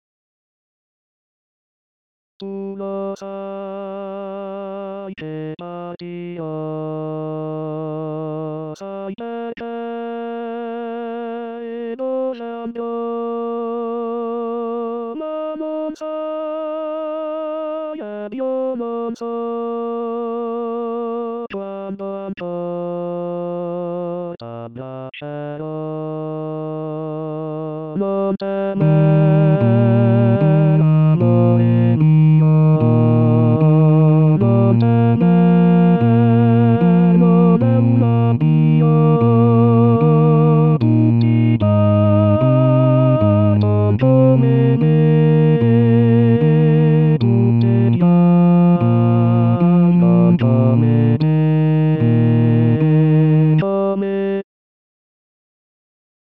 La Ballata del soldato basses 2 bas.mp3